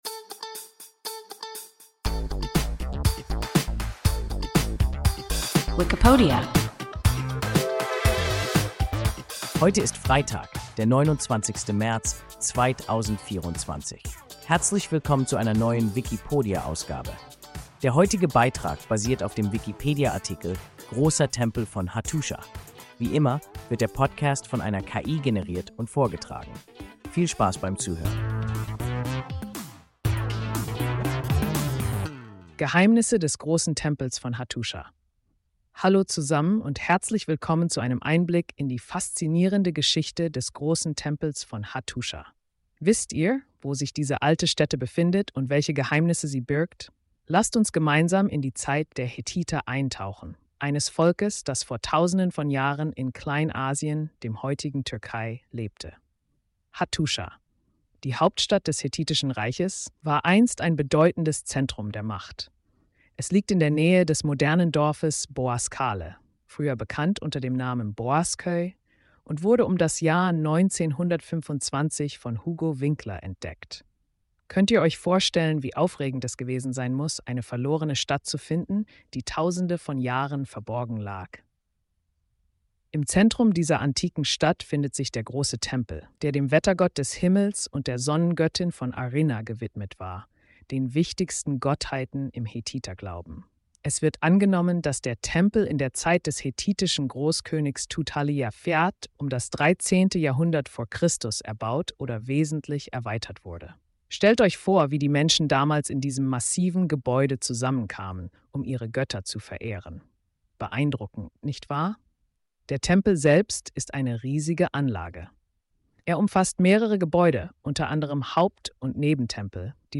Großer Tempel von Ḫattuša – WIKIPODIA – ein KI Podcast